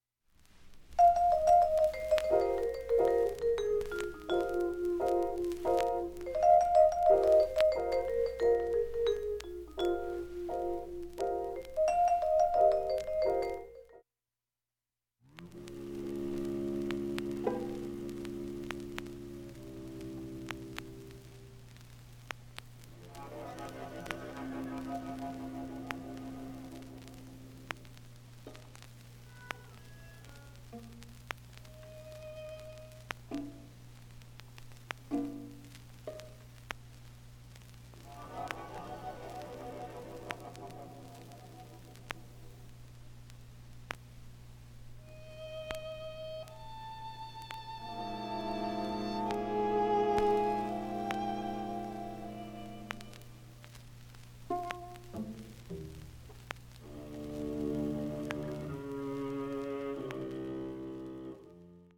音質良好全曲試聴済み。
B-１中盤に４ミリスレで４８回かすかなプツ出ます。
◆ＵＳＡ盤オリジナル Mono